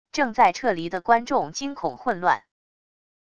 正在撤离的观众惊恐混乱wav音频